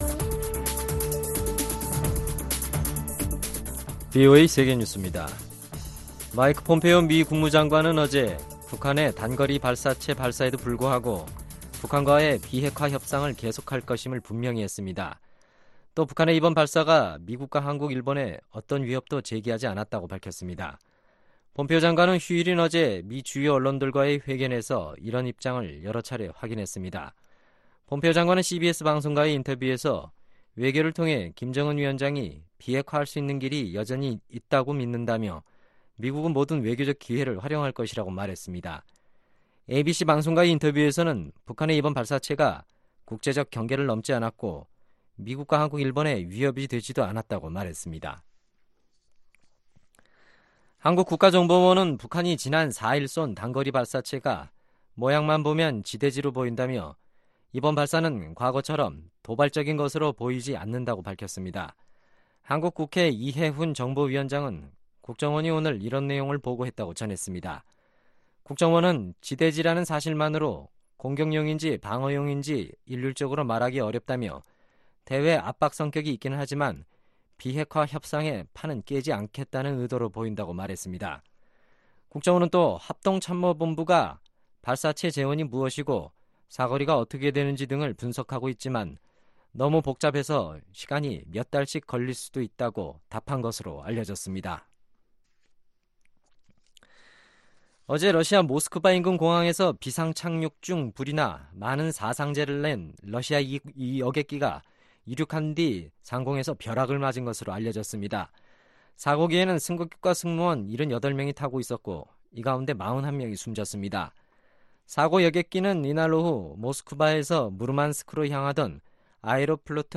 VOA 한국어 간판 뉴스 프로그램 '뉴스 투데이', 2019년 5월 6일 3부 방송입니다. 마이크 폼페오 미 국무장관은 북한의 단거리 발사체 발사에도 불구하고 북한과 대화를 계속하기를 바란다고 말했습니다. 북한의 이번 발사는 위협을 통해 존재감을 드러내려는 전형적인 대미 압박이라고 미국의 전직 관리들이 분석했습니다.